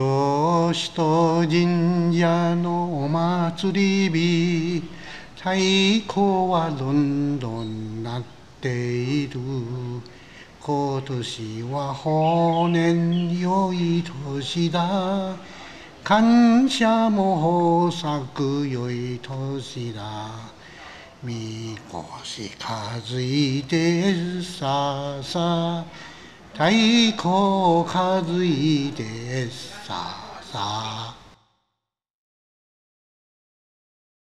橋頭にて、「日本統治時代」を知る老人と会い、その内の何人かはこの歌を口ずさんで下さった。